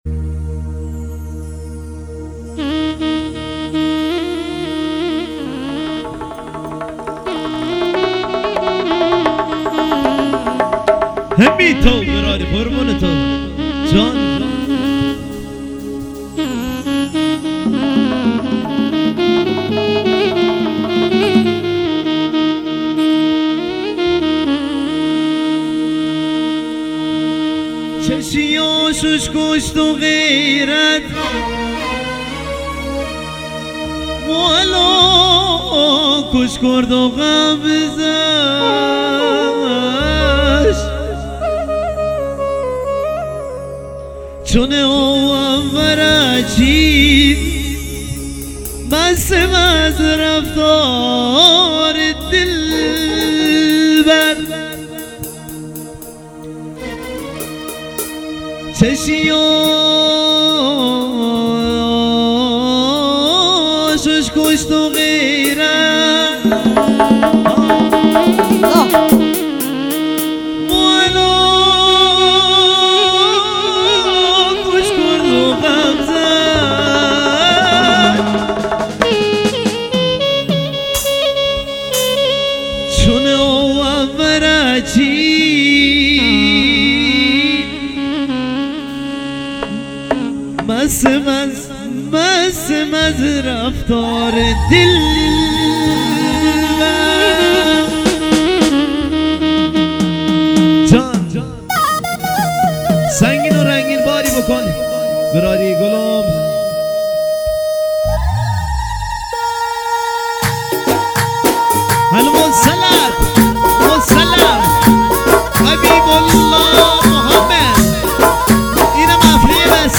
کیبورد
درامز